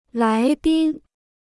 来宾 (lái bīn): Laibin prefecture-level city in Guangxi; guest.